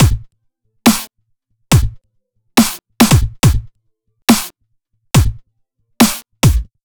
Drumloop_01_B (OLD).wav